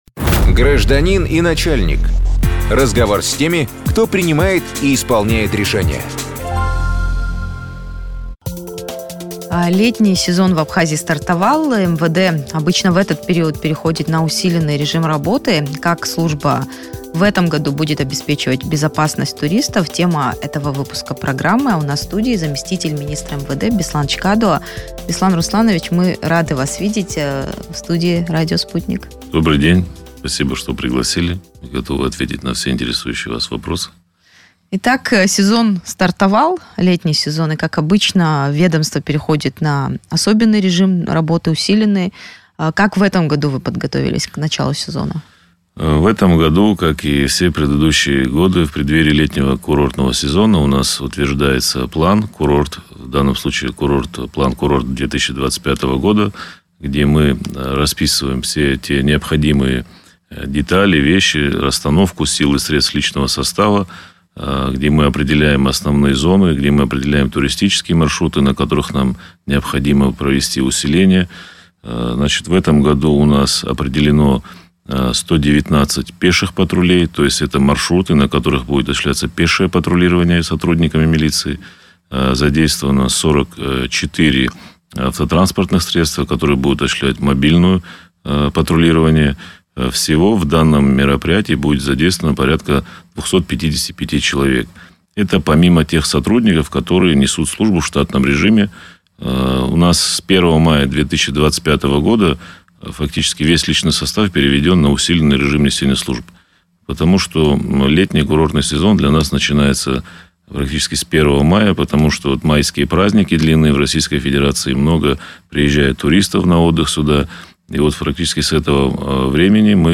Интервью с замглавы МВД: охрана правопорядка летом